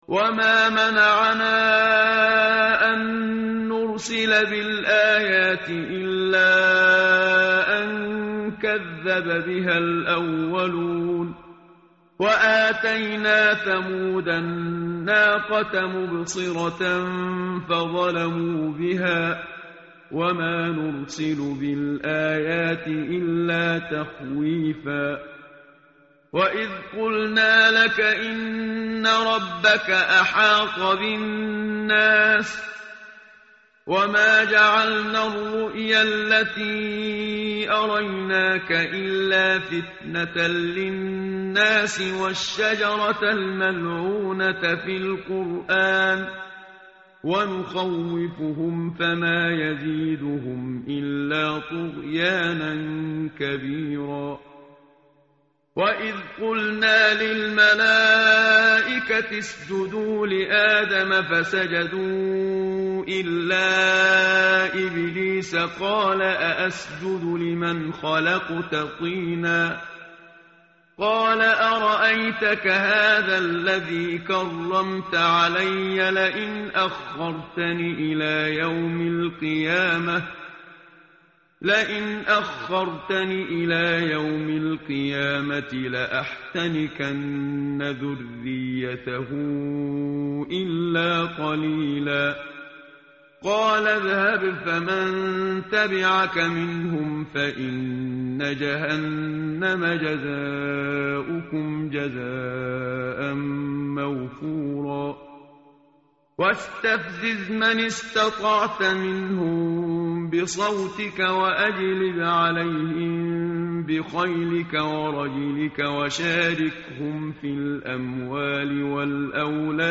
tartil_menshavi_page_288.mp3